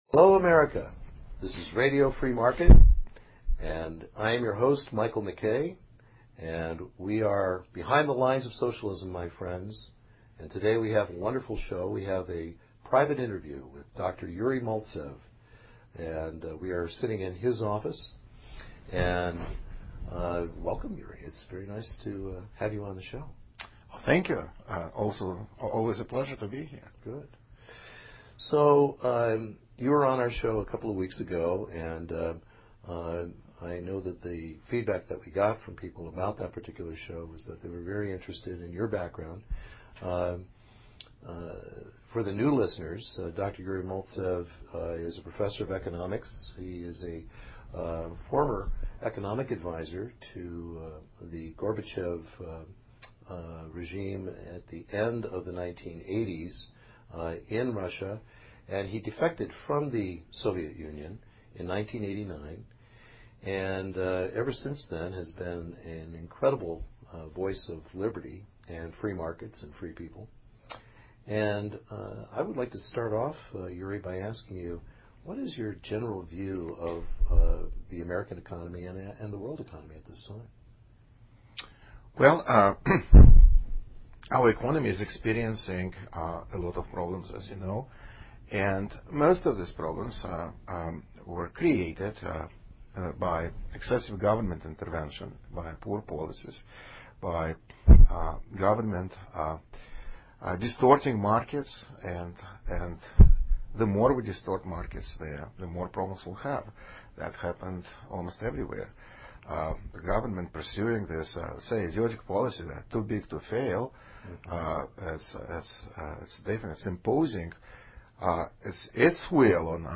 This is a timely and important interview that you will want everyone you care about to hear.